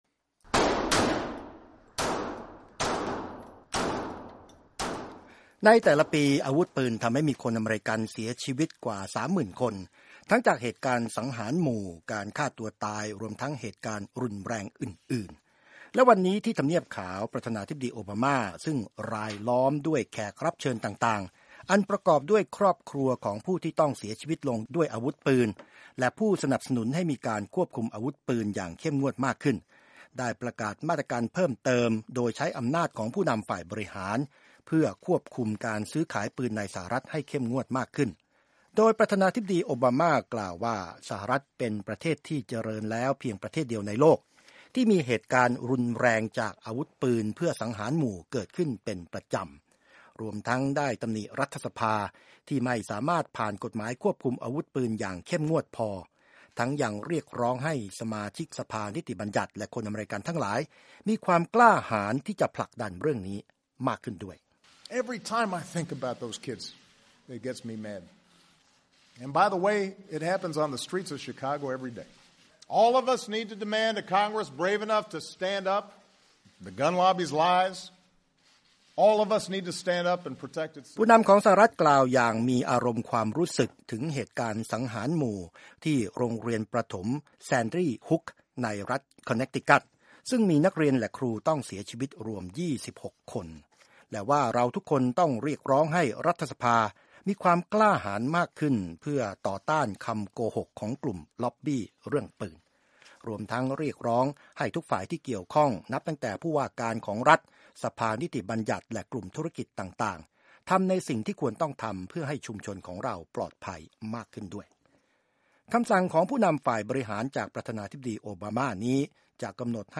ประธานาธิบดีโอบาม่าถึงกับหลั่งน้ำตาขณะกล่าวถึงเหยื่อผู้เสียชีวิตจากความรุนแรงจากอาวุธปืนหลายรายในสหรัฐฯในช่วงปีที่ผ่านมา